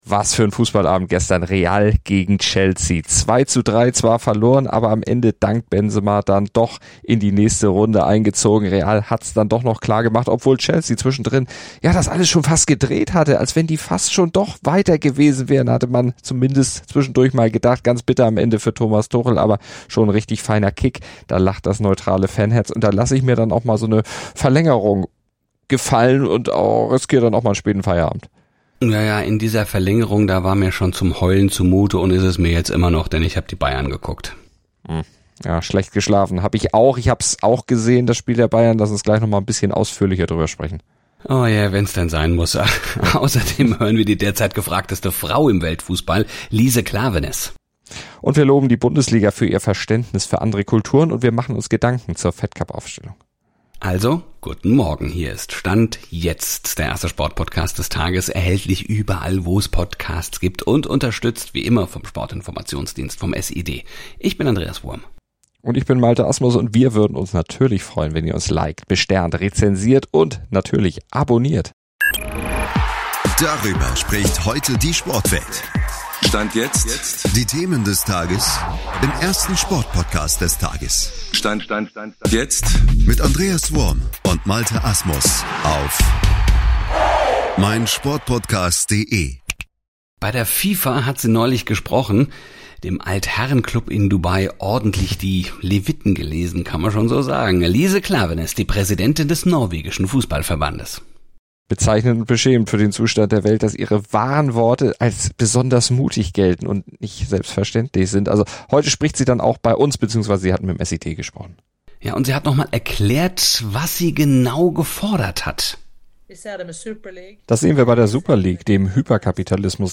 Sie sprechen drüber, hören Lise Klaveness im SID-Interview zu, loben die Bundesliga für ihr Verständnis für andere Kulturen und machen sich Gedanken zur Tennisaufstellung der deutschen Frauen.